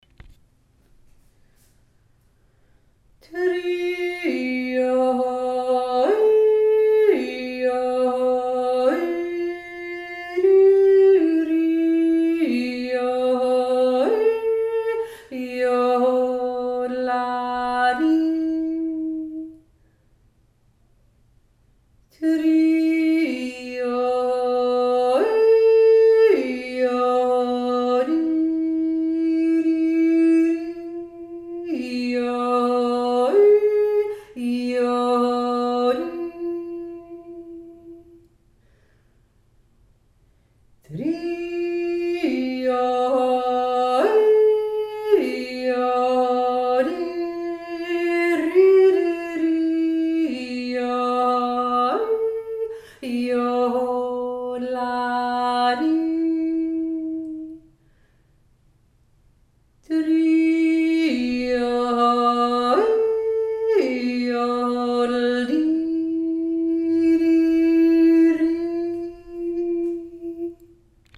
barmstoaner-1-stimme.mp3